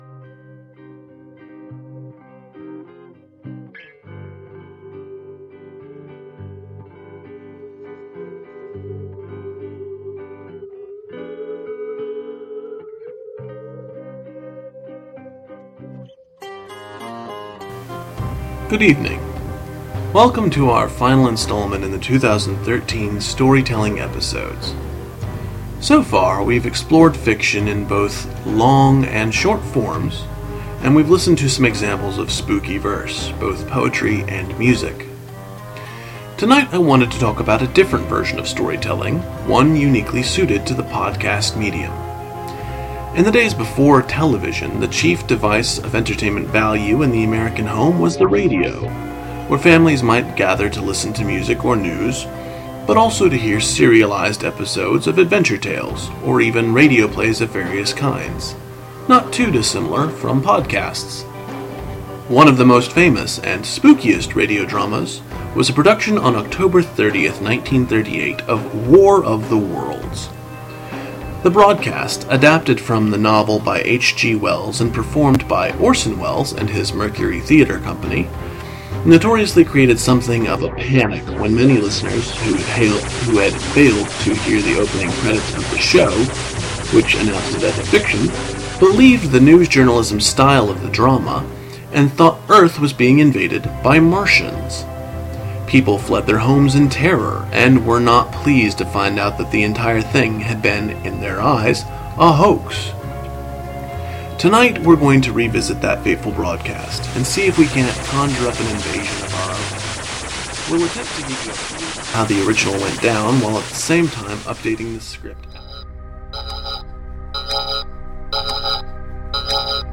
Summary In this episode, we experience some witchy radio drama and NIGHT VALE NIGHT VALE.